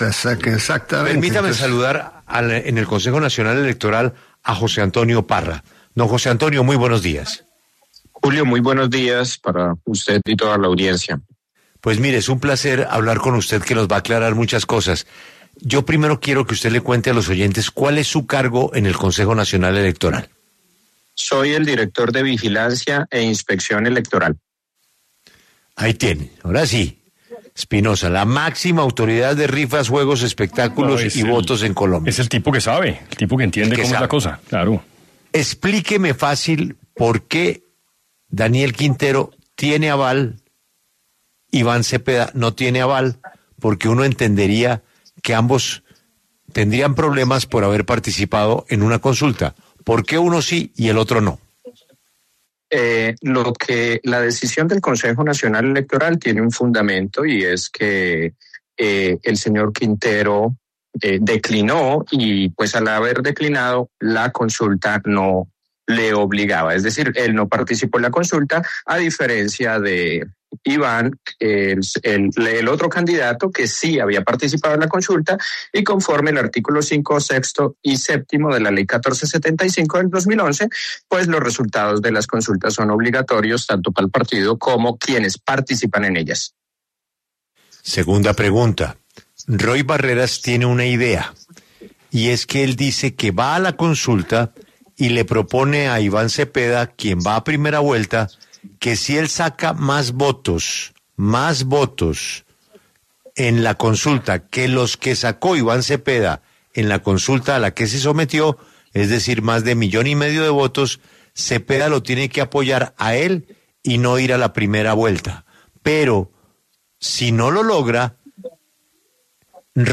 El director de Vigilancia e Inspección Electoral, José Antonio Parra, explicó en 6AMW la decisión del Consejo Nacional Electoral.